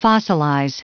Prononciation du mot fossilize en anglais (fichier audio)
Prononciation du mot : fossilize